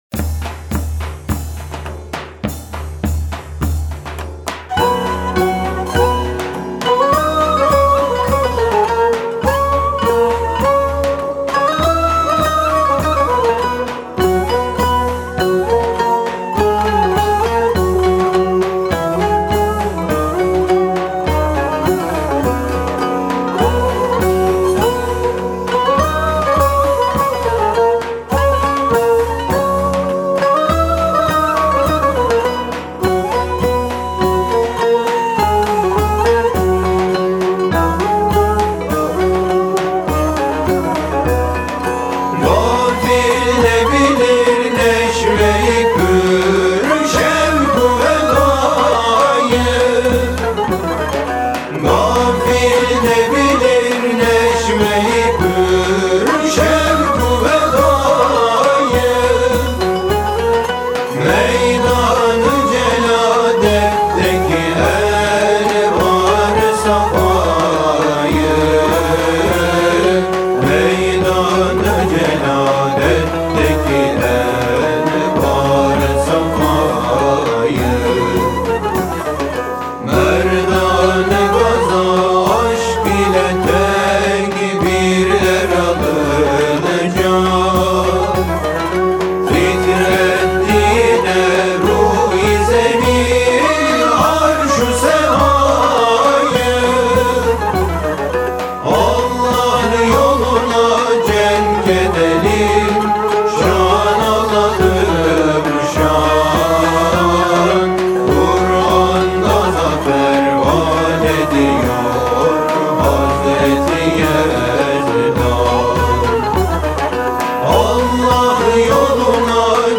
Makam: Mahur Form: Marş Usûl: Nim Sofyan